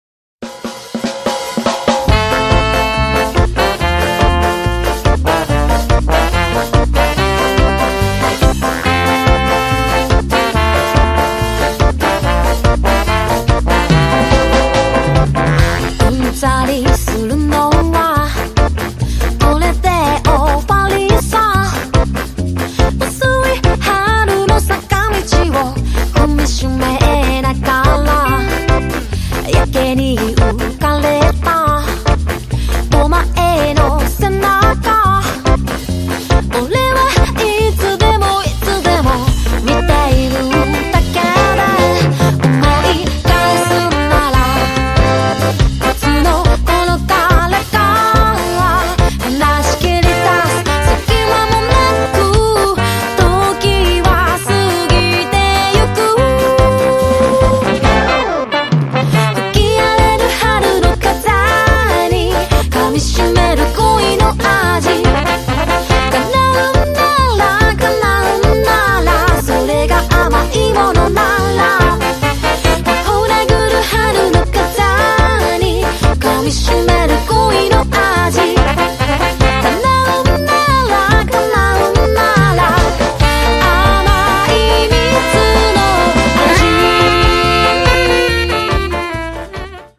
形式 : 7inch / 型番 : / 原産国 : JPN
歌謡スカ・バンド
REGGAE / SKA / DUB